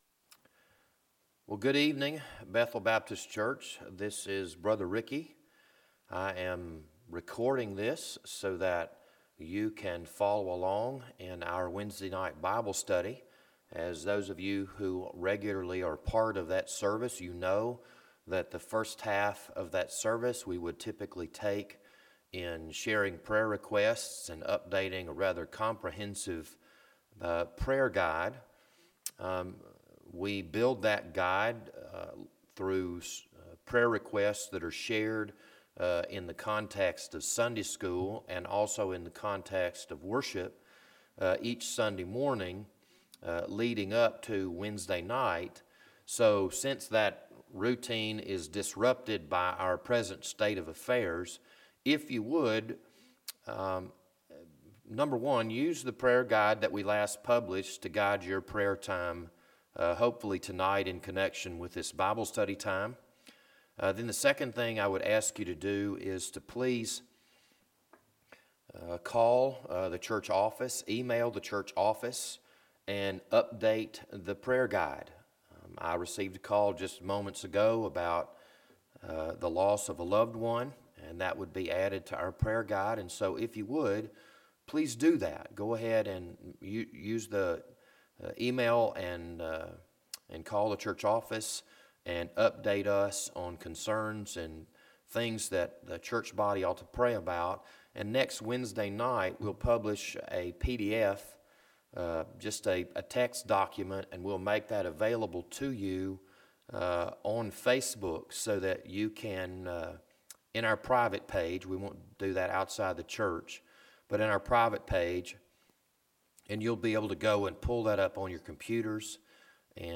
This Wednesday evening Bible study was recorded on March 25th, 2020.